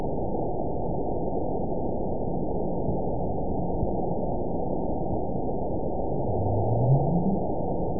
event 920346 date 03/17/24 time 20:59:05 GMT (1 year, 1 month ago) score 9.60 location TSS-AB03 detected by nrw target species NRW annotations +NRW Spectrogram: Frequency (kHz) vs. Time (s) audio not available .wav